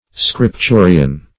\Scrip*tu"ri*an\